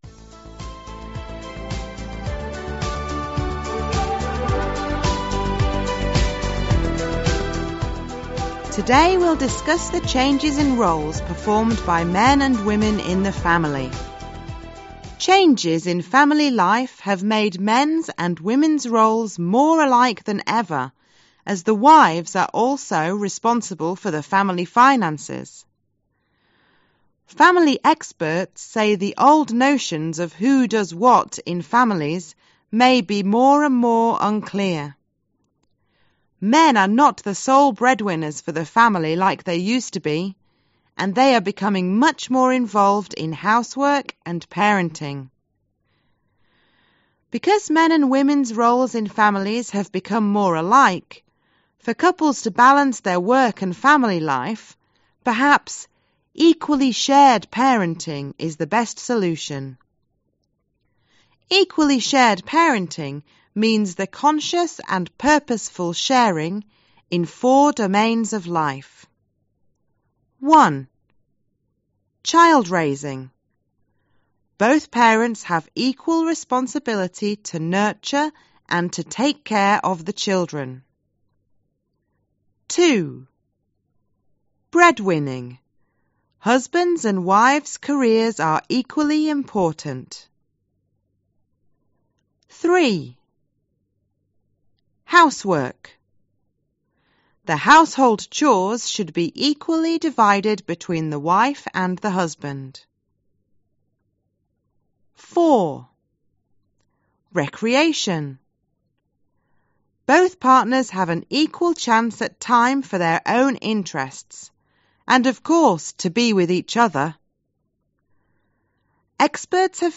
Listen to a family expert talking about how the roles of men and women in families have changed and decide whether the following statements are true (T) or false (F): Lắng nghe một chuyên gia gia đình nói về việc vai trò của đàn ông và phụ nữ trong gia đình đã thay đổi như thế nào và chọn đúng (T) hay sai (F) cho các nhận định dưới đây.